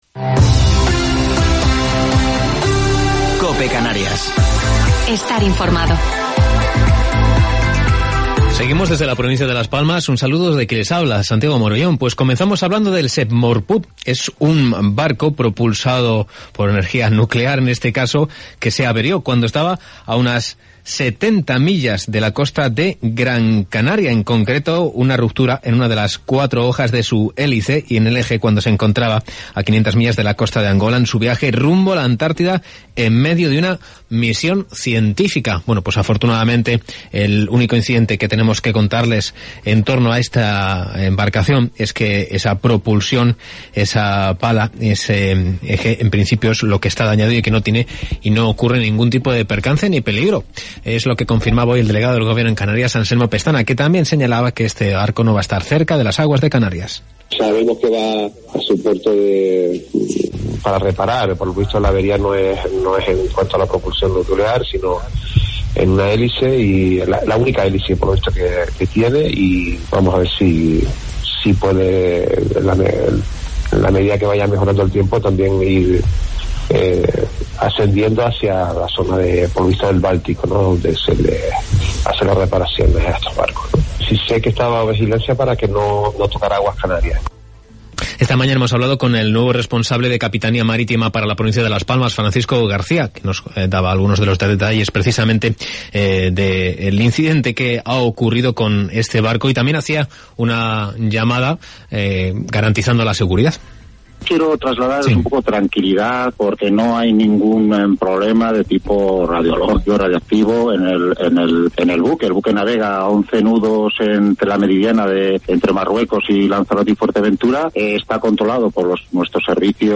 Informativo local 16 de Diciembre del 2020